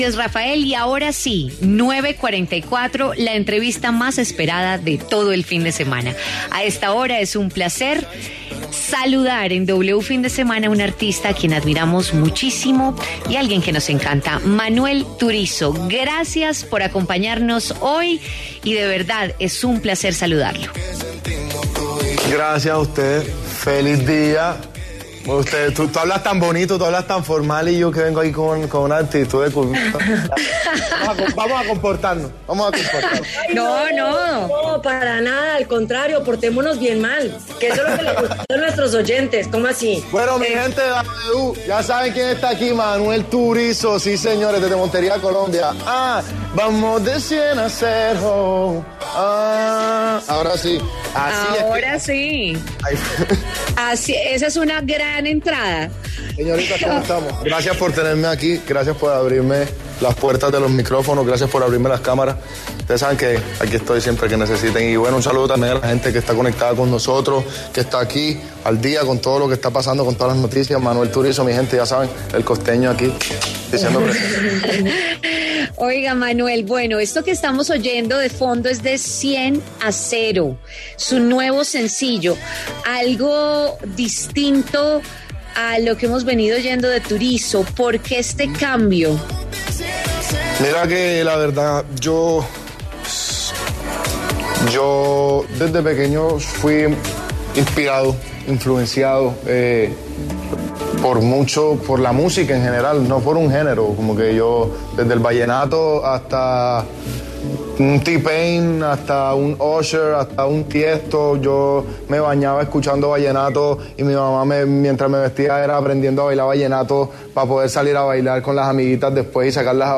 Manuel Turizo, cantante colombiano, habló en W Fin de Semana a propósito del lanzamiento de su canción “De 100 a 0” y reveló que está próximo a lanzar nuevo disco.